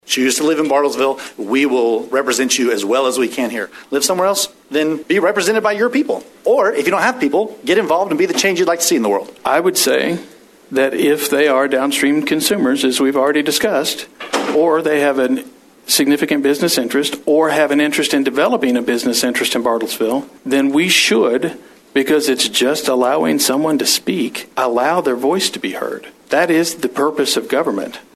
Public Comment a Topic of Conversation at Monday's Council Meeting
Here are council members Aaron Kirkpatrick and Tim Sherrick sharing their thoughts